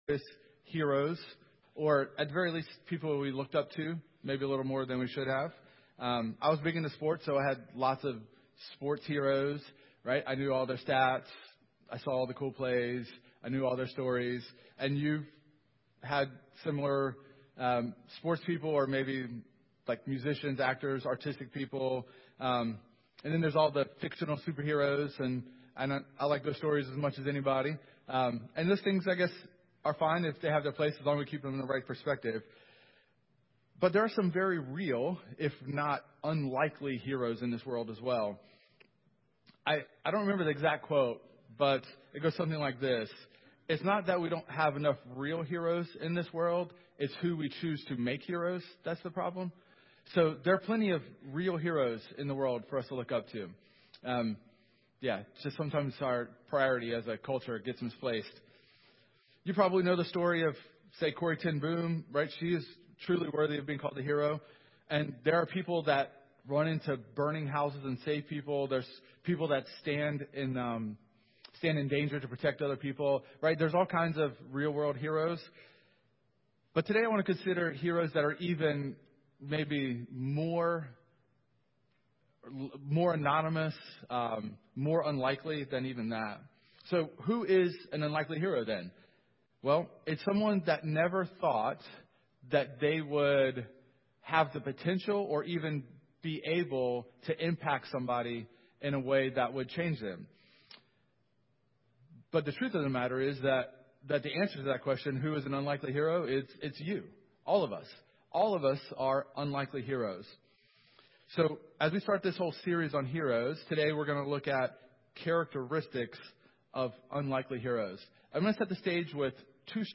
2 Thessalonians 3:6-12 Service Type: Sunday Morning Our "Hero Hotline" Vacation Bible School is just a few weeks away
Topics: Heroes share this sermon « Nehemiah & Discouragement Called Together to…